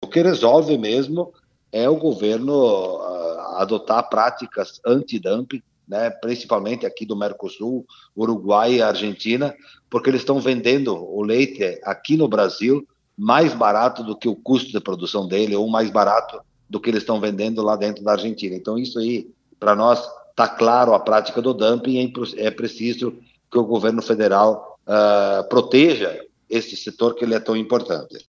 E o tema do leite foi tratado hoje pela manhã, às 6 e 30, no programa Progresso Rural da RPI.